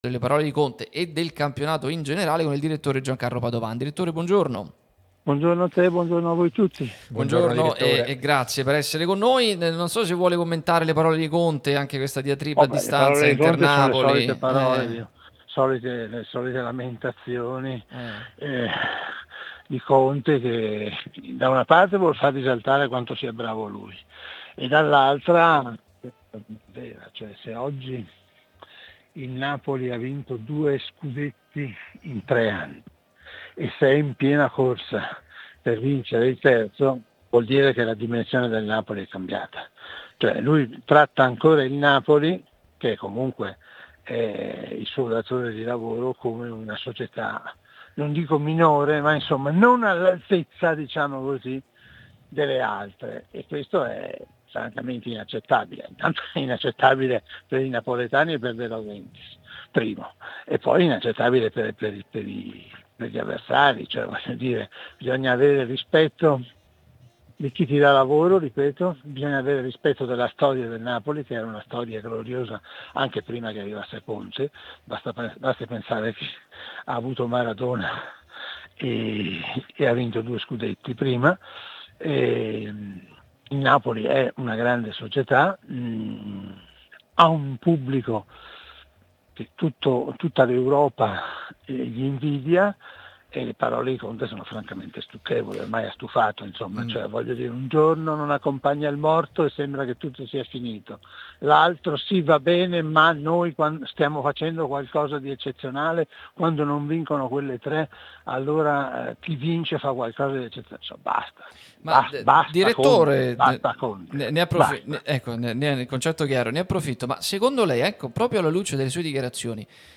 Giancalo Padovan è intervenuto nel corso di Pausa Caffè sulla nostra Radio Tutto Napoli, prima radio tematica sul Napoli, in onda tutto il giorno, che puoi ascoltare/vedere qui sul sito o sulle app (qui per Iphone/Ipad o qui per Android) criticando l'ultima uscita di Conte sul gap strutturale dalle milanesi e la Juventus: "